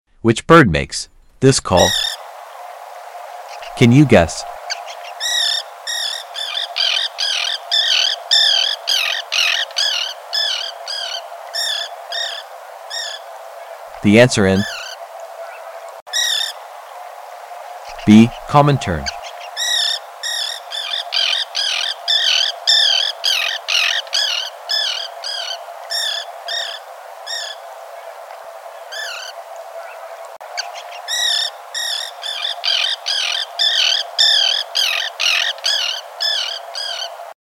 Which bird makes this call..?..Can sound effects free download
bird call bird sounds